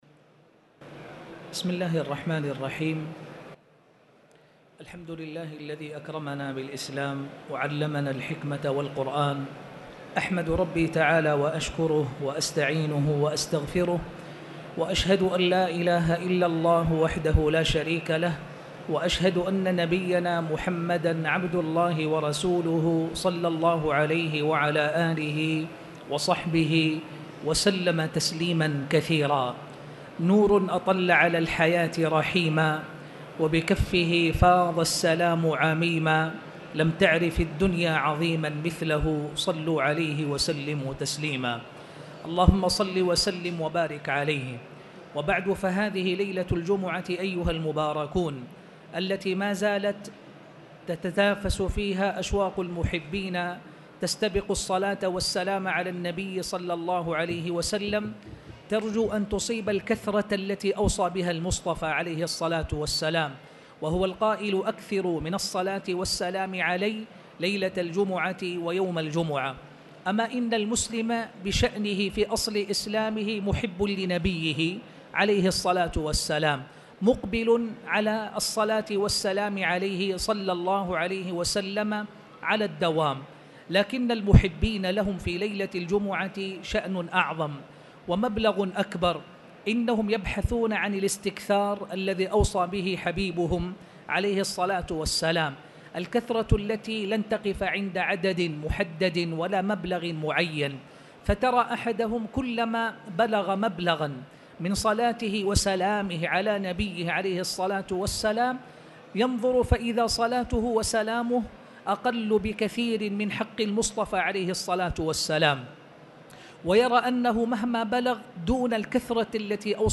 تاريخ النشر ١٧ صفر ١٤٣٨ هـ المكان: المسجد الحرام الشيخ